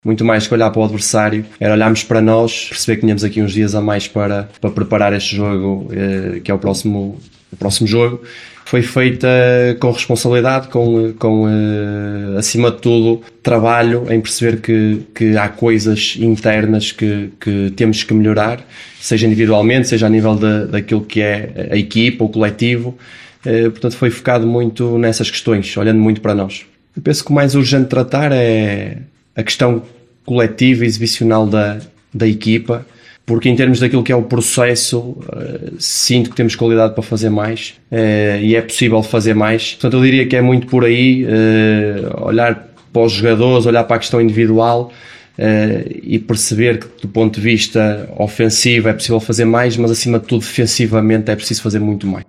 Na habitual conferência de imprensa de antevisão à partida, o treinador do Vitória traçou os objetivos para este encontro.
Declarações